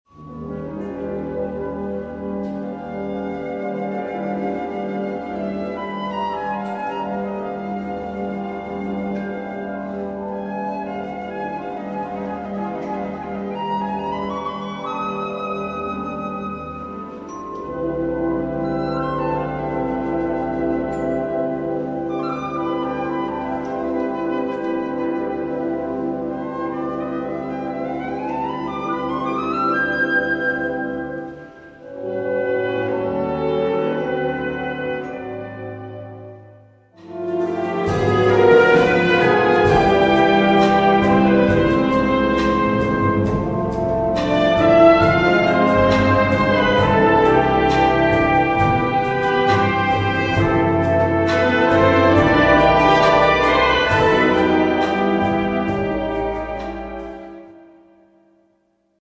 • Besetzung: Blasorchester
eine symphonische Jazz-Ballade